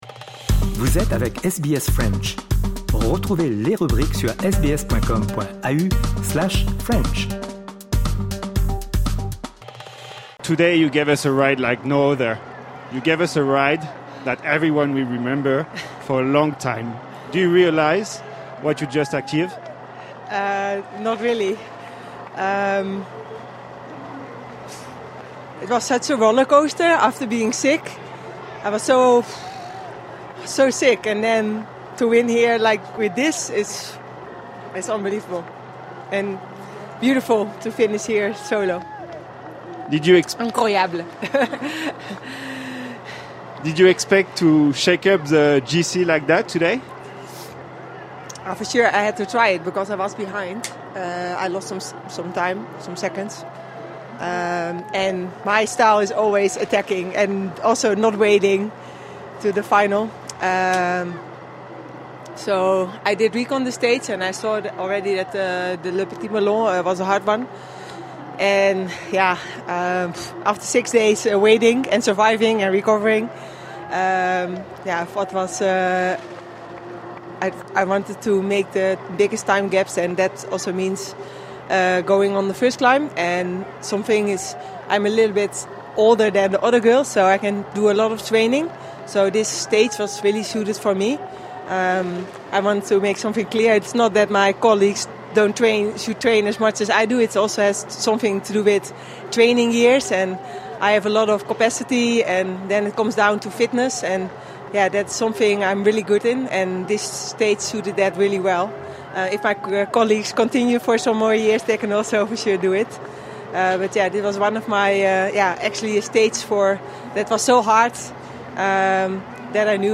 La Néerlandaise Annemiek van Vleuten a remporté la septième étape du Tour de France Femmes, la plus difficile du parcours avec plus de 3 000 mètres de dénivelé dans le massif des Vosges. Elle s'empare ainsi du maillot jaune et prend une sérieuse option pour la victoire finale. Interview.